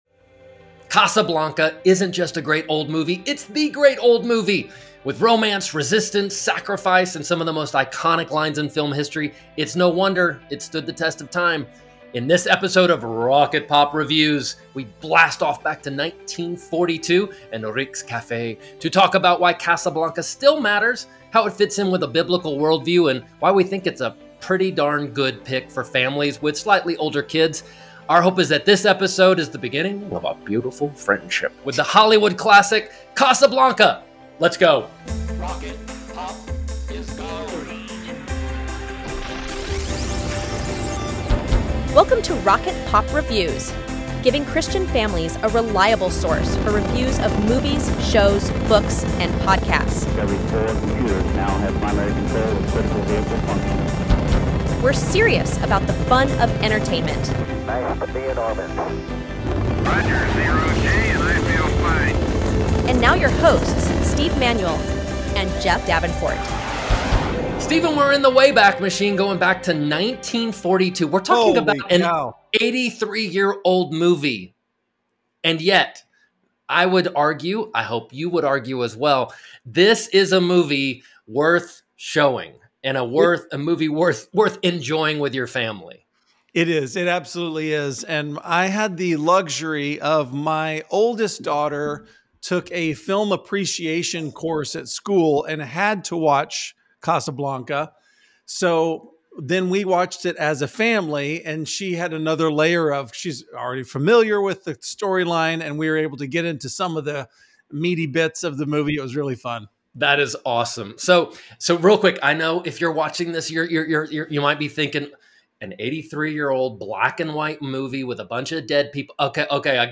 CASABLANCA | Movie Review